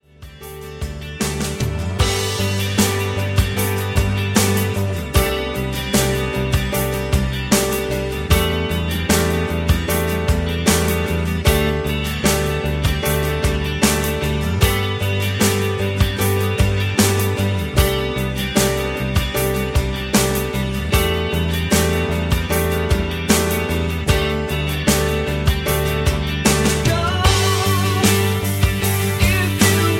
Backing track Karaoke
Pop, Rock, 1990s